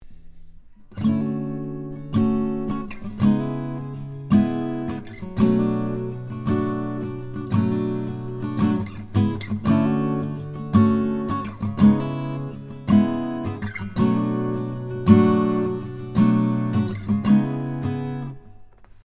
7th chord progression example 4
Progression 4 chords are D Major 7, C# minor 7, and B minor 7.
(On the audio, I play the last chord on beat 4 the first time and on beat 3 the second time).